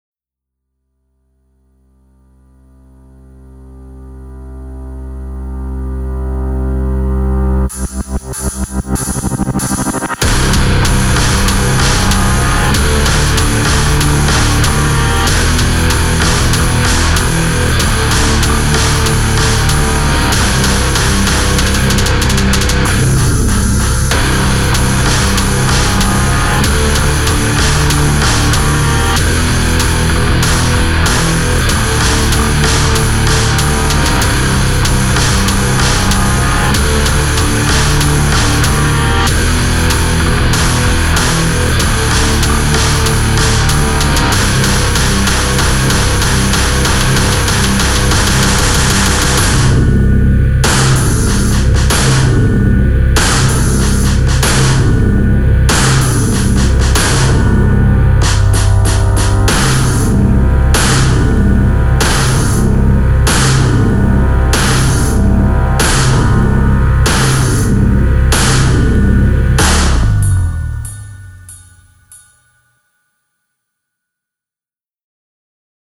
BGM
ショートロック